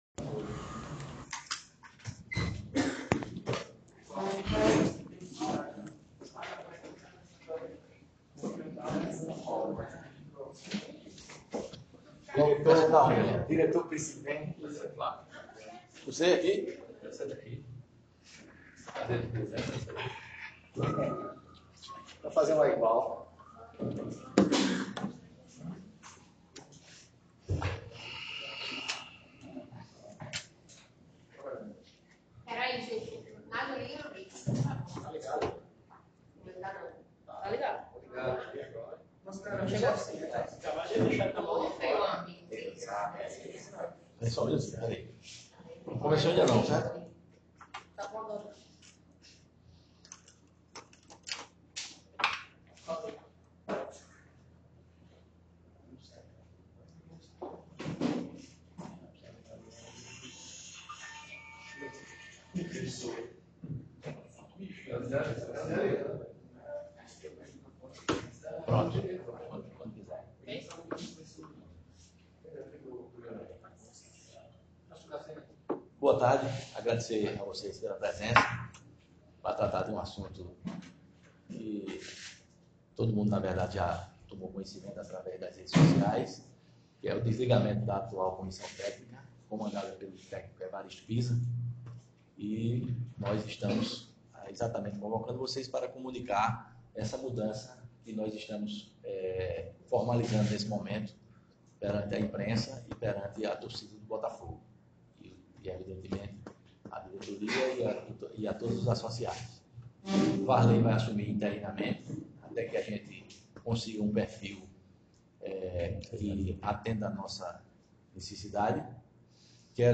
O Podcast Soesporte traz a entrevista completa, Ouça …